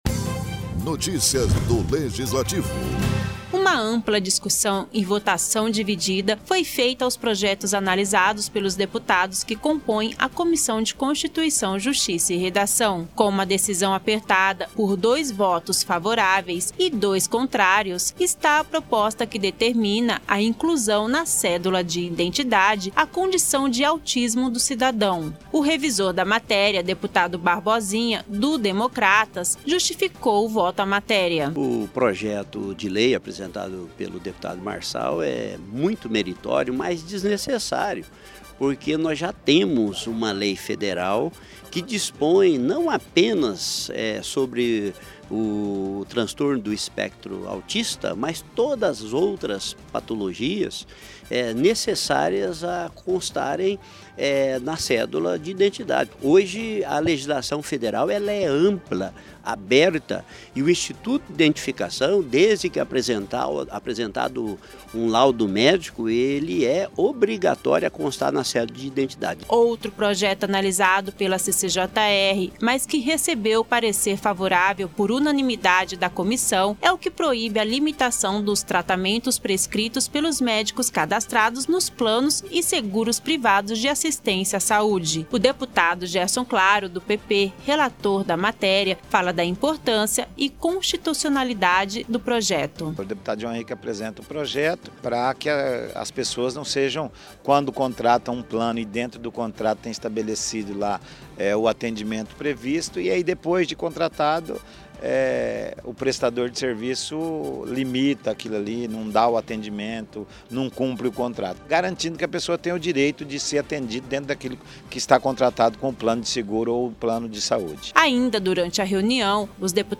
Locução e Produção